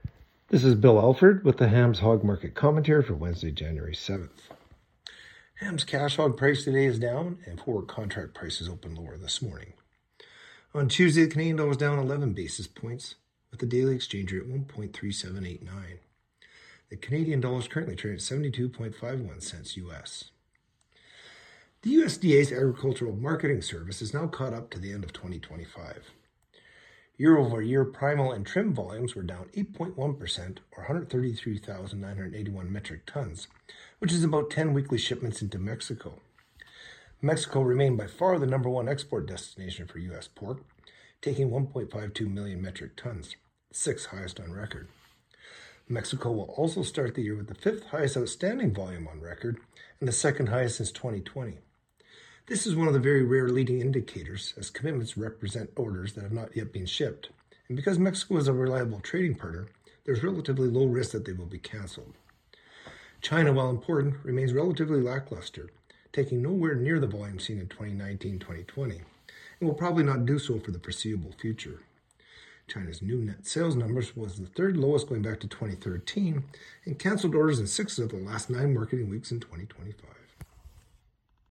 Hog-Market-Commentary-Jan.-7-26.mp3